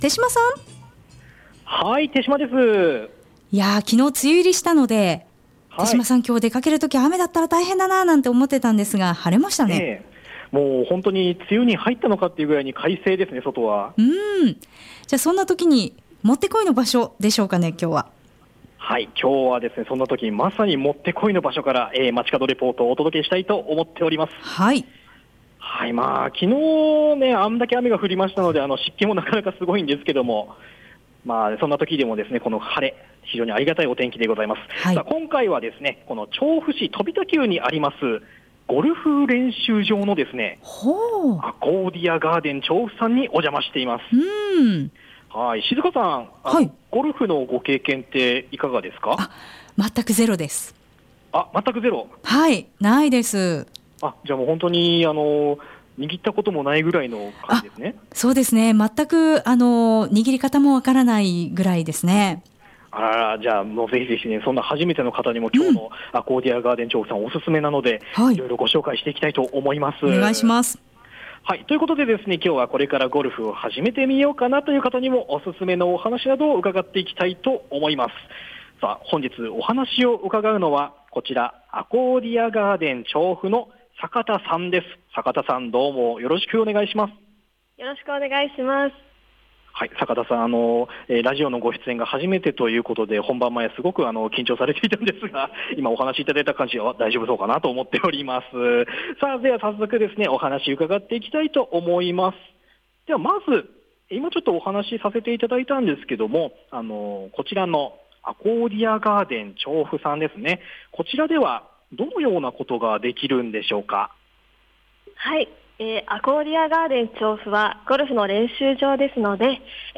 ◆本日の放送音声はこちら↓ 午後のカフェテラス 街角レポート 2018-06-07(木) 青空の下、鳴り響くボールを打つカキーンという音が爽快ですね。